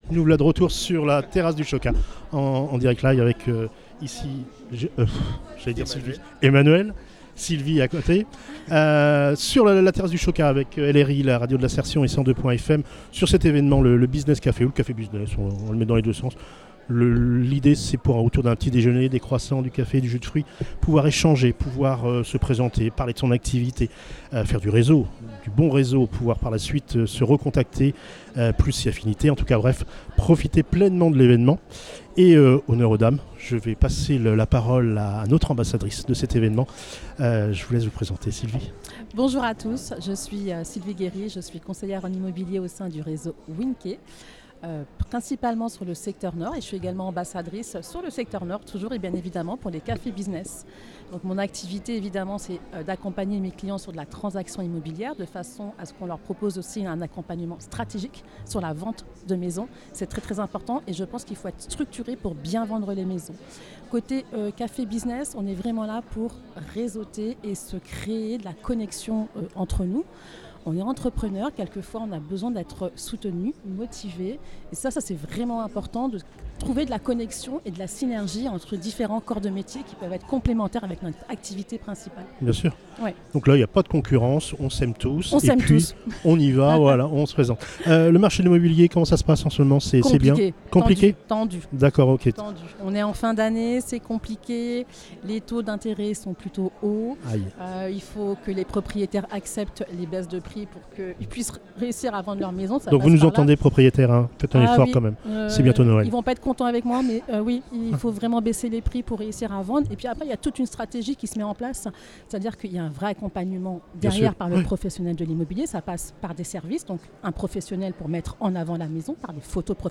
Un échange inspirant et riche en idées pour tous ceux qui souhaitent se lancer, innover ou dynamiser leur activité professionnelle.